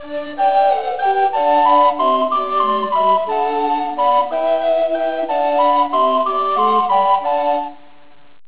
Recorders Sound Clips
Although the four members of a recorder consort are usually soprano (descant), alto (treble), tenor and bass - heard together on the sound clip which can be downloaded above - the photograph also features a smaller sopranino recorder, ideal in earlier times for providing dance music out of doors because of its high pitch and loud tone.